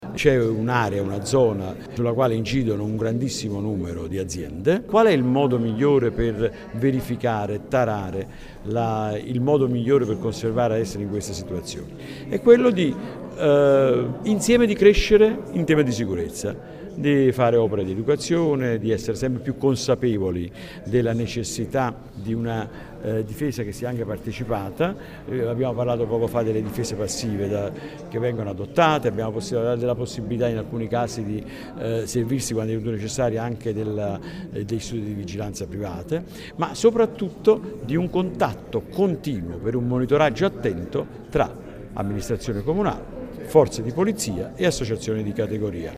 Ascolta il prefetto Angelo Tranfaglia